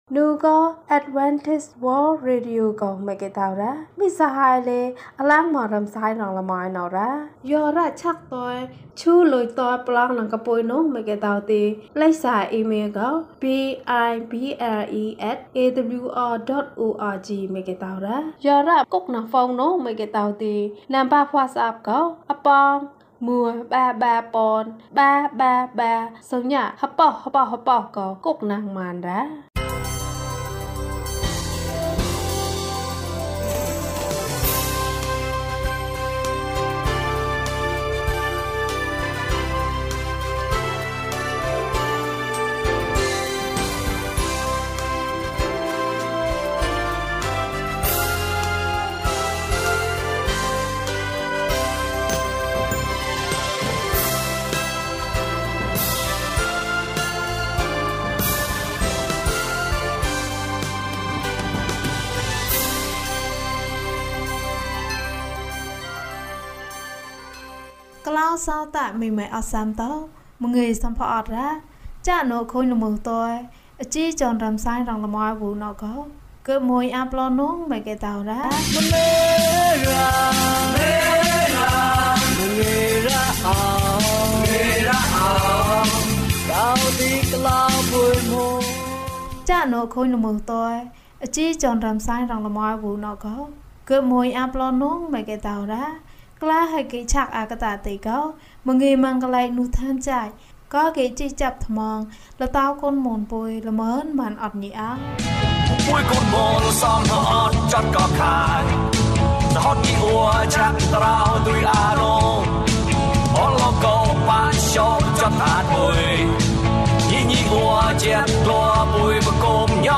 သူသည် အမှန်တရားဖြစ်သည်။၀၁ ကျန်းမာခြင်းအကြောင်းအရာ။ ဓမ္မသီချင်း။ တရားဒေသနာ။